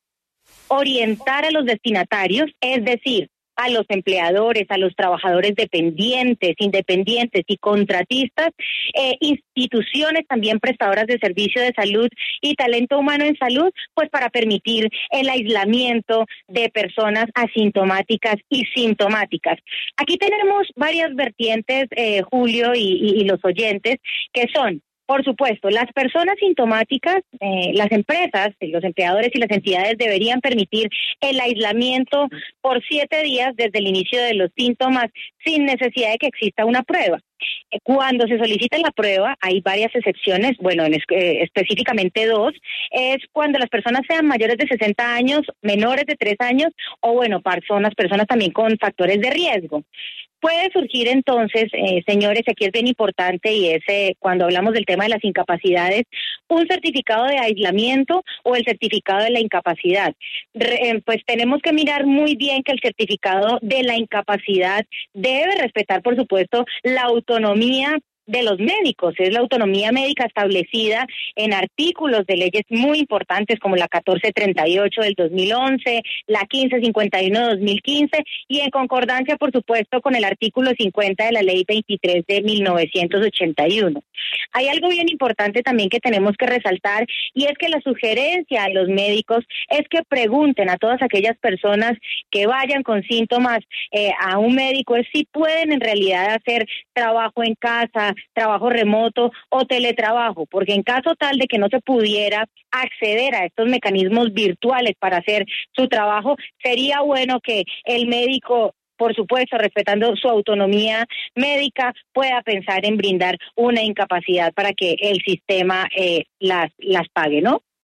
En diálogo con la W, Isis Andrea Muñoz, viceministra de Relaciones Laborales e Inspección del Ministerio del Trabajo explicó.